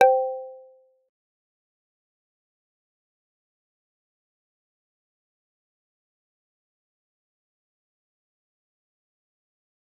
G_Kalimba-C5-mf.wav